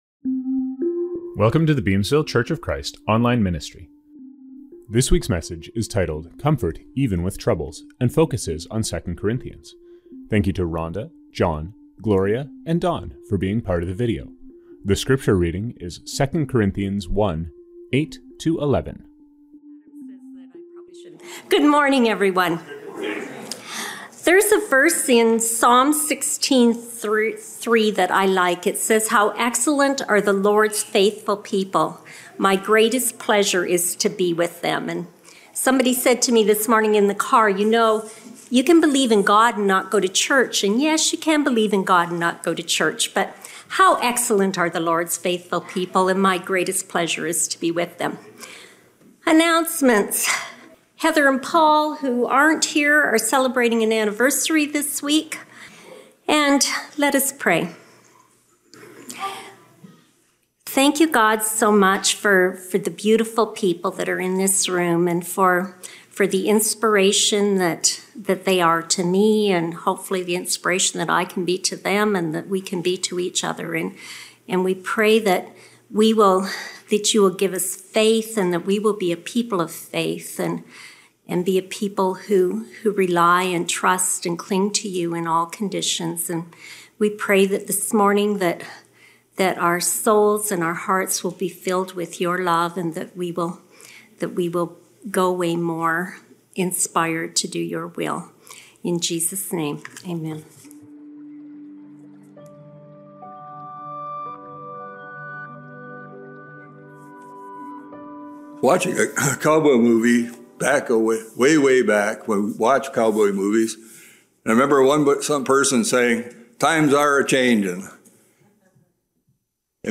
Scriptures from this service: Welcome - Psalm 16:3 (GNT).
Reading - 2 Corinthians 1:8-11.